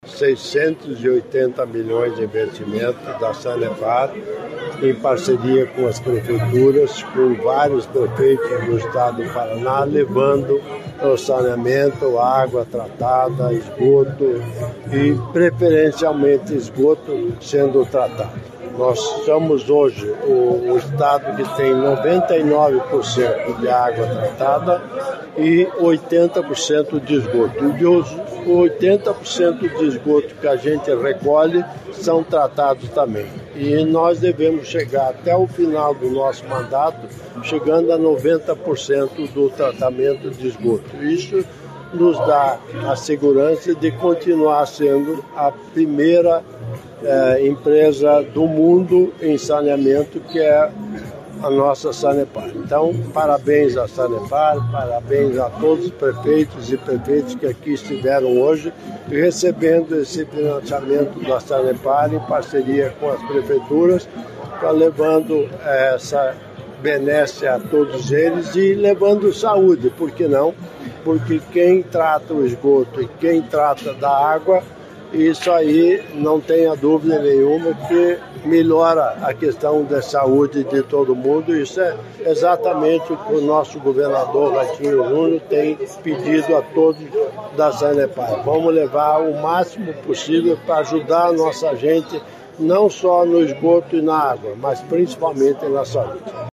Sonora do governador em exercício Darci Piana sobre a parceria entre as prefeituras e a Sanepar